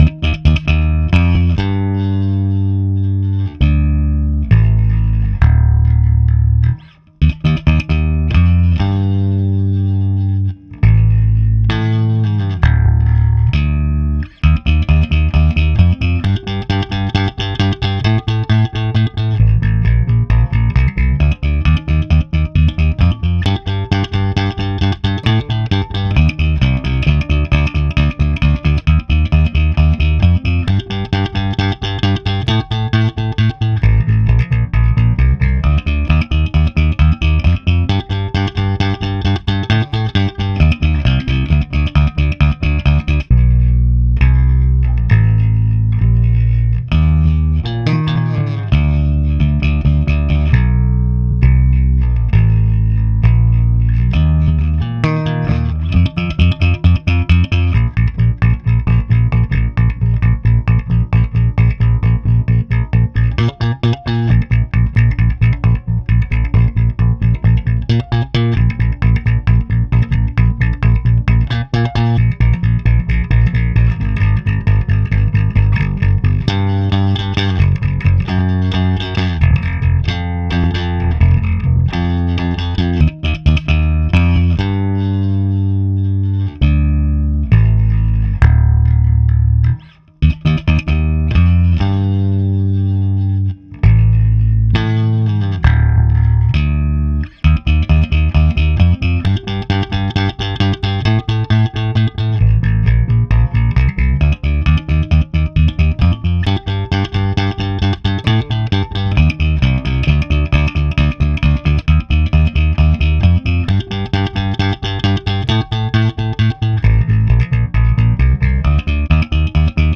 habe anstelle dessen mal ein kleines experiment gemacht und den bass mit meinem neuen 6-saiter selber eingespielt.
das ist mit finger gespielt.
ja, ich mag den bass-sound vom dingwall sehr. sehr mixerfreundlich...
hier gehts zu der spur mit meinem bass:
dingwall.wav